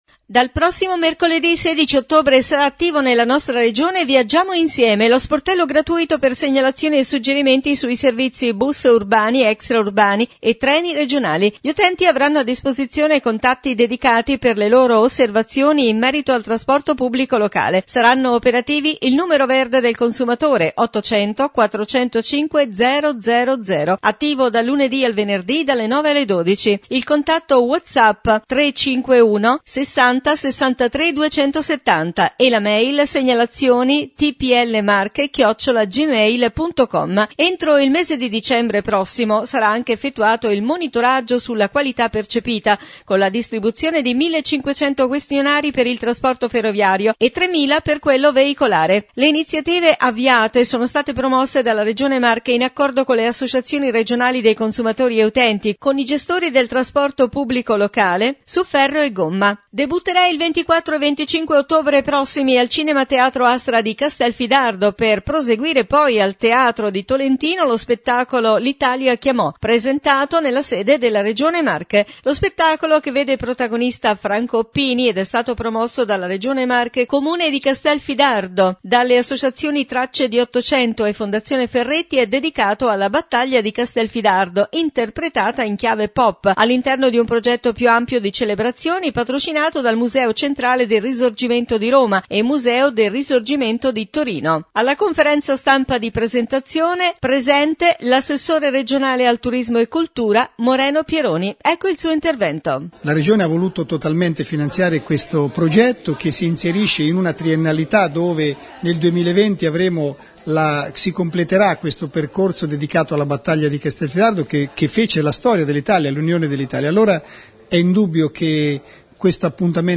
Notizie Regione … Debutterà il 24 e 25 ottobre al Cinema Teatro Astra di Castelfidardo per proseguire poi al Teatro Vaccaj di Tolentino lo spettacolo teatrale “L’Italia chiamò” Interviste Moreno Pieroni – Assessore Turismo e Cultura Franco Oppini – protagonista